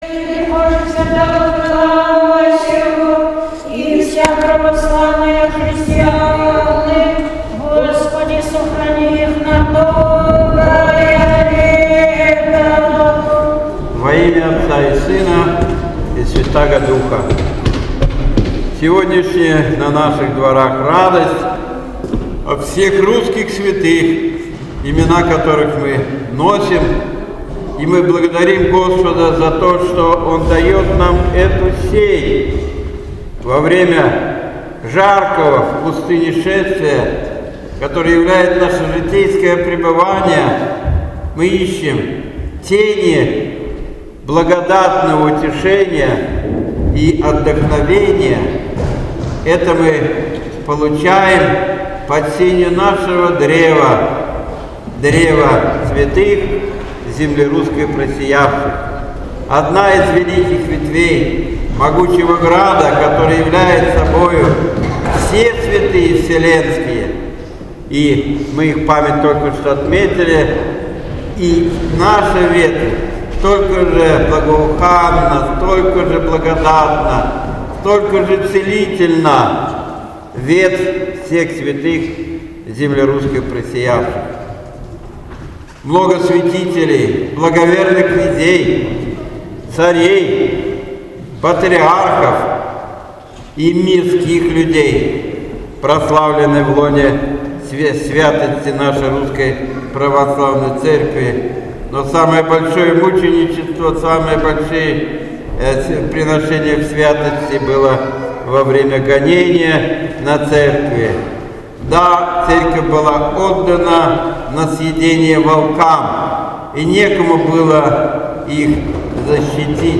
Воскресное Богослужение 18 июня 2017 года.